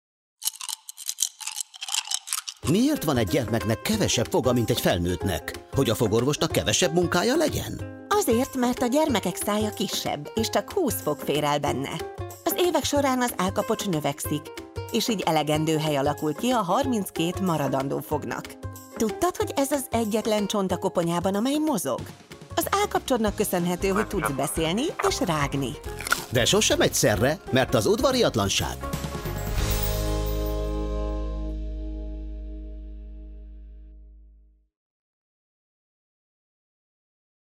Hallgasd meg, milyen hangja van az állkapocsnak rágás közben!
A sorozat II. lapszámához tartozó játék sztetoszkóppal meghallgatható bizonyos testrészek hangja, valamint lejátszható egy rövid párbeszéd a testrésszel kapcsolatos érdekességekről.